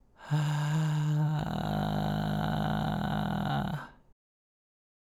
次は、喉頭を上げながらパーの声で「え」か「あ」と発声し、息が無くなるまで出していると、緩いじりじり音が出てきます。
※見本音声(「あ」の音)